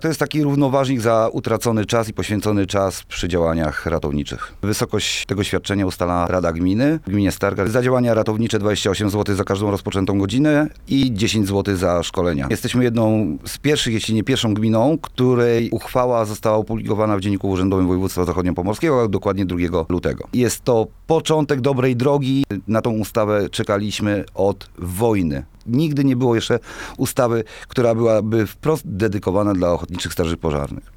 Mówi Przewodniczący Rady Gminy Stargard Sebastian Janiak.